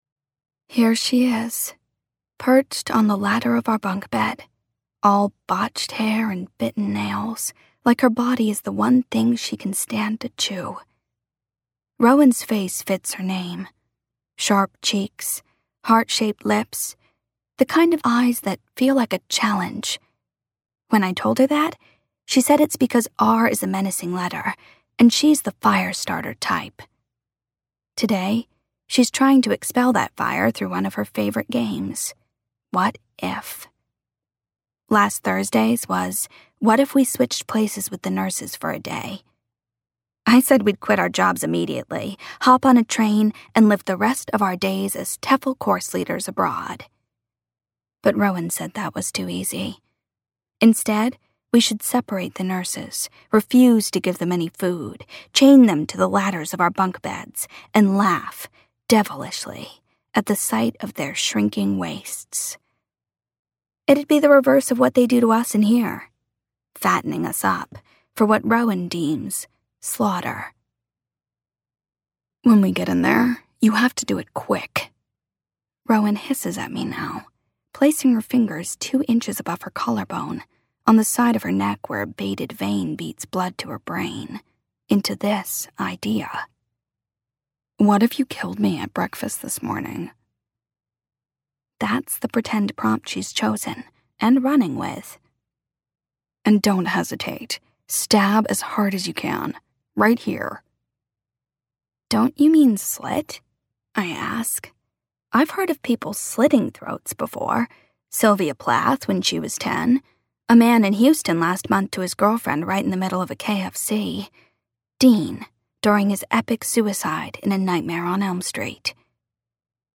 Before We Were Blue - Vibrance Press Audiobooks - Vibrance Press Audiobooks